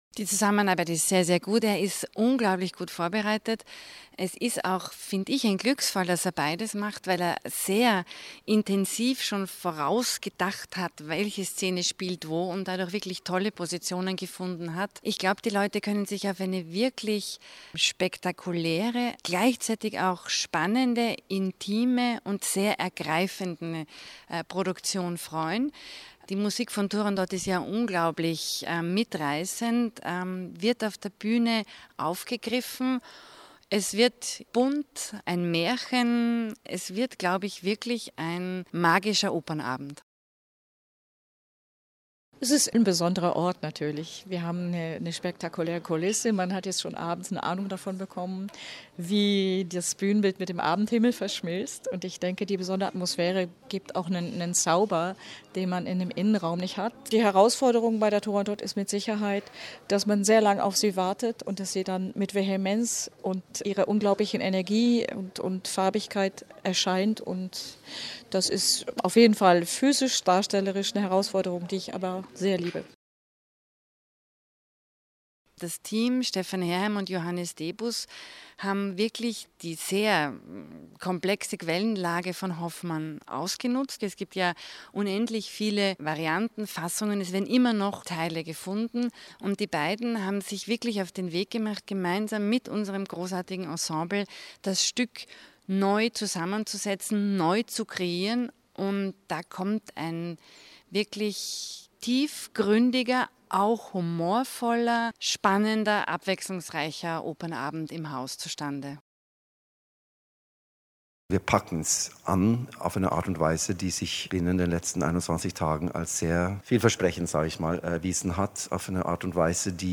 O-Töne - Pressetag - Feature